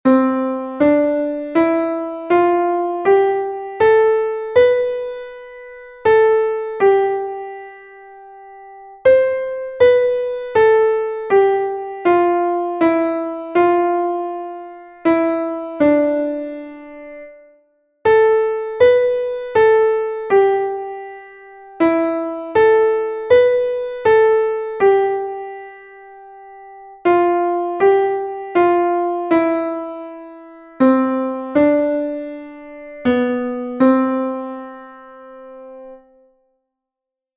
2_melodia_6x8.mp3